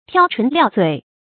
挑唇料嘴 tiāo chún liào zuǐ
挑唇料嘴发音